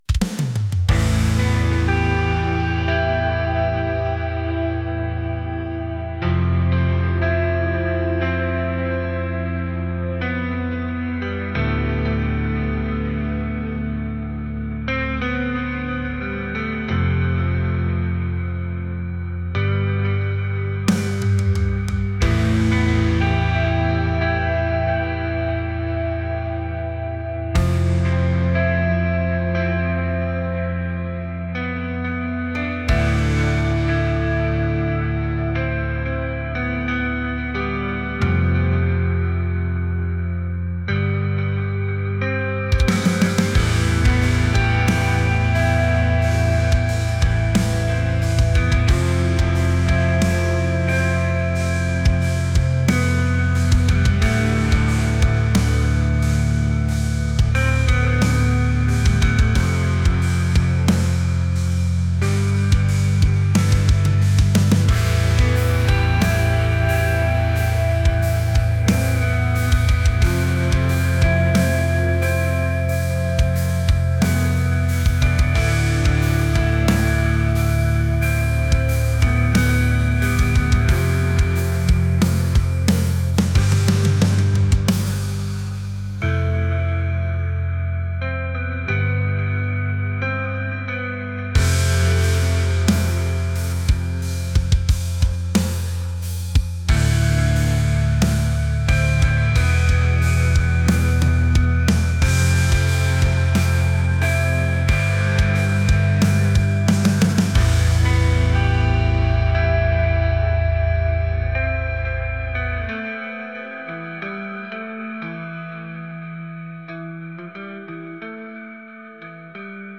atmospheric | alternative | rock